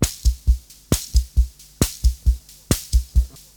• 67 Bpm Classic Drum Loop Sample C Key.wav
Free drum loop - kick tuned to the C note. Loudest frequency: 2149Hz
67-bpm-classic-drum-loop-sample-c-key-XB5.wav